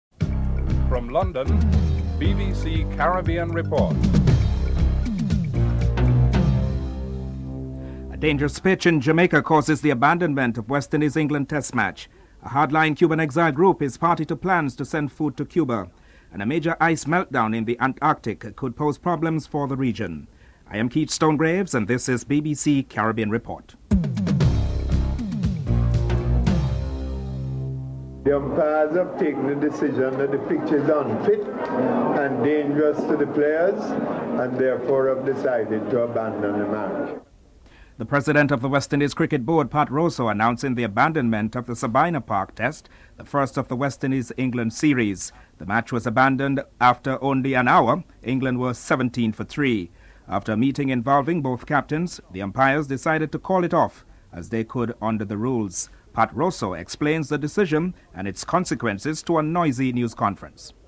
Jamaicans express their disappointment and disgust about this cricketing fiasco and English supporters who traveled across the sea are even more perturbed.